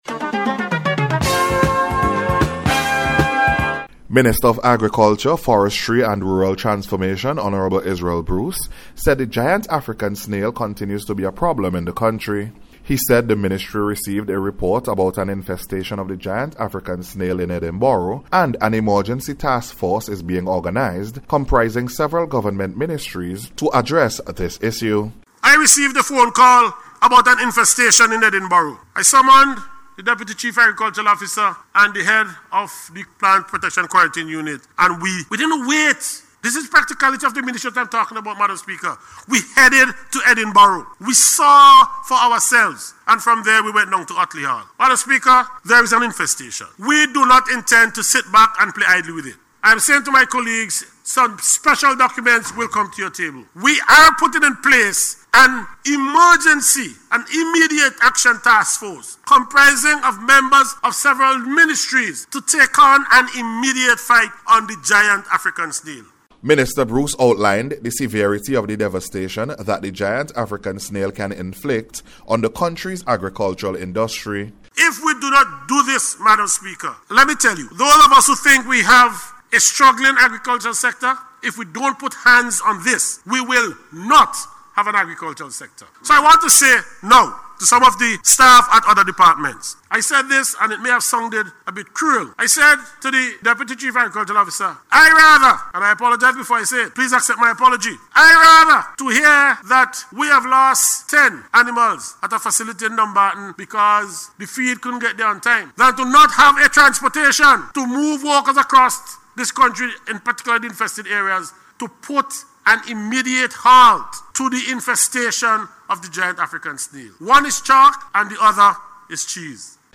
GIANT-AFRICAN-SNAIL-INFESTATION-REPORT.mp3